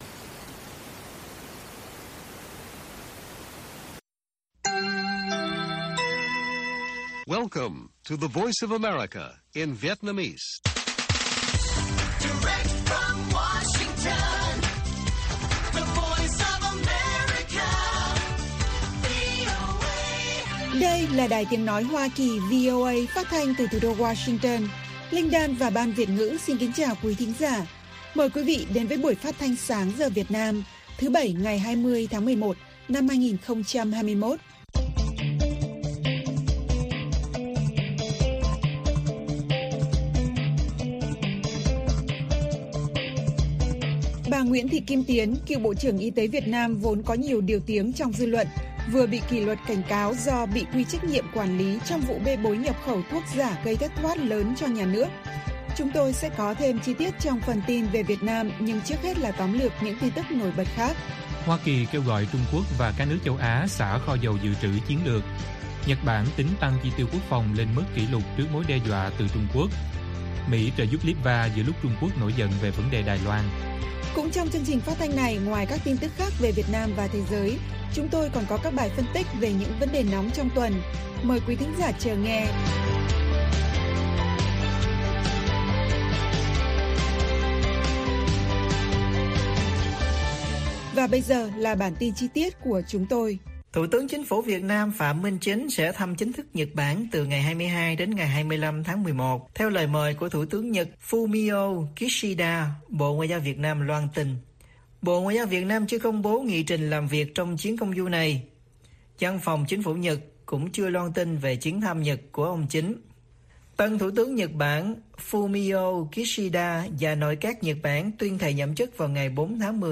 Bản tin VOA ngày 20/11/2021